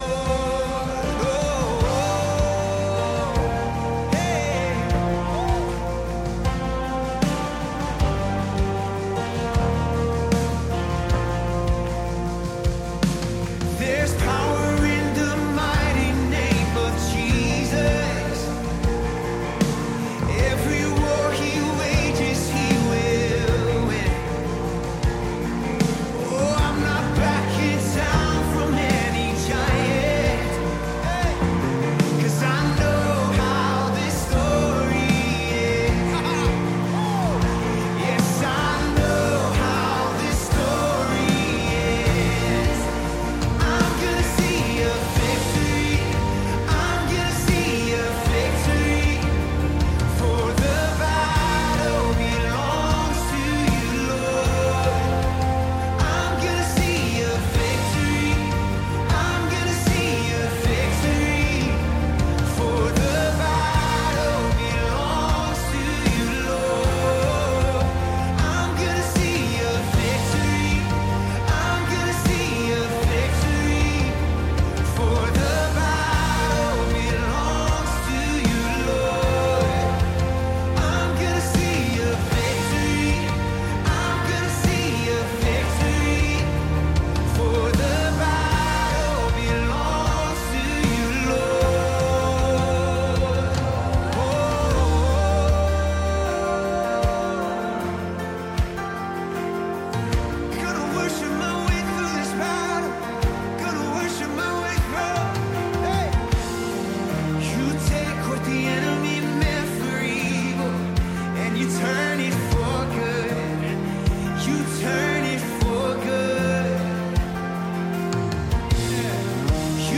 Calvary Knoxville Sunday PM Live!